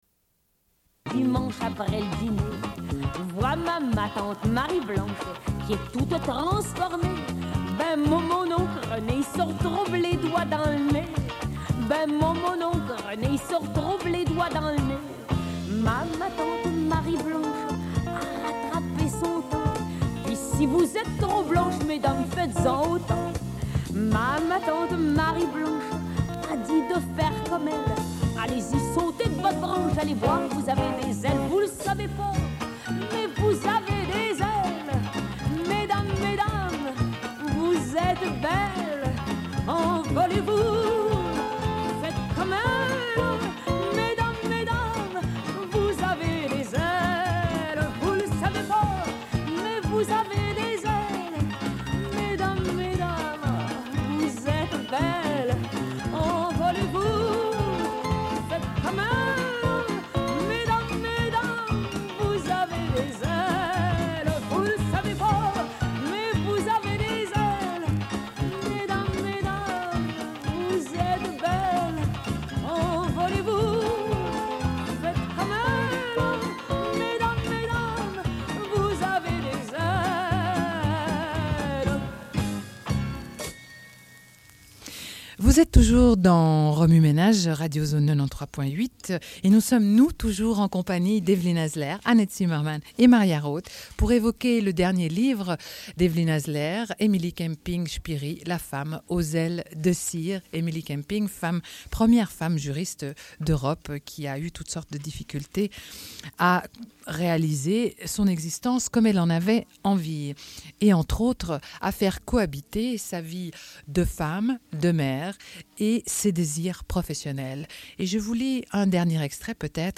Une cassette audio, face A31:49